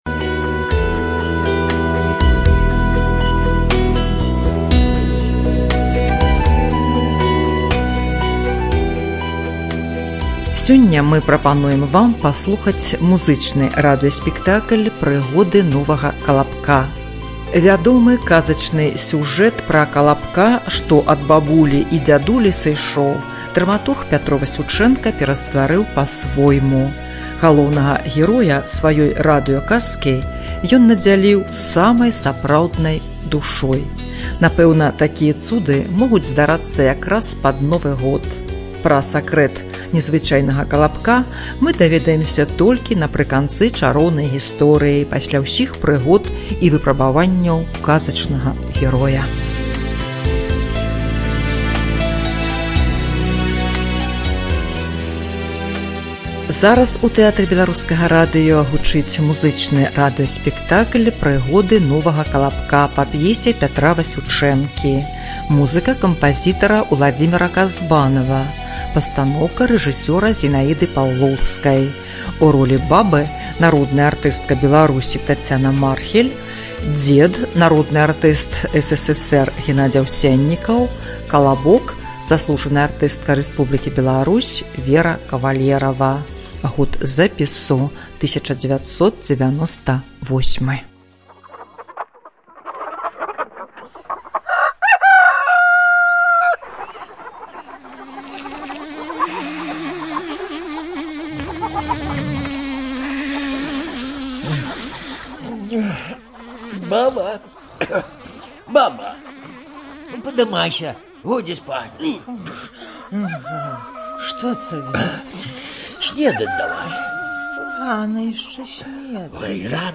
ЖанрРадиоспектакли на белорусском языке